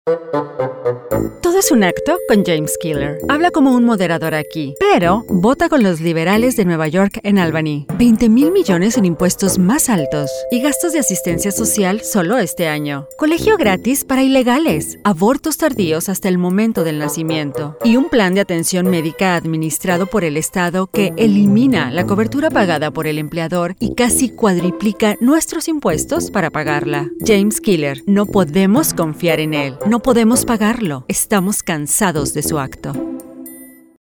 Female Spanish Republican Political Voiceover
Spanish attack ad